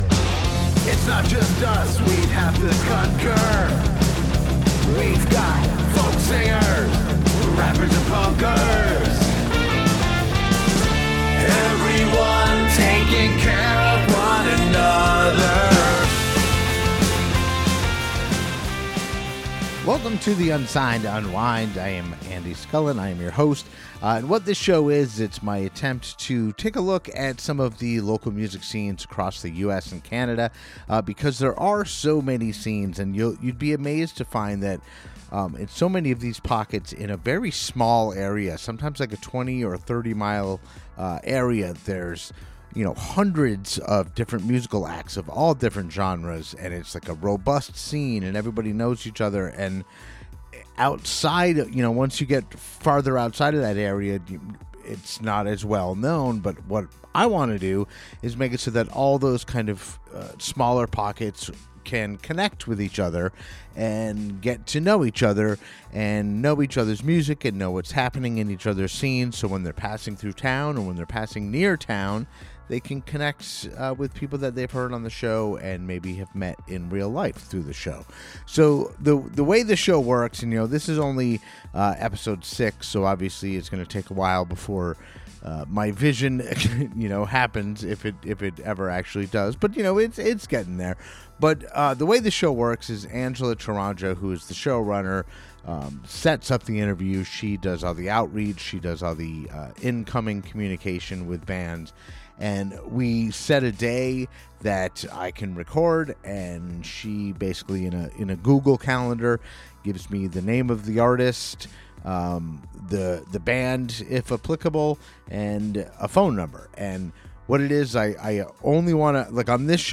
This show is an attempt to gather great local music from all over the US and Canada, have a brief conversation with the band/musician and play one of their songs. My goal is that local-music enthusiasts, such as myself, can discover great local music that otherwise may have remained hidden to them.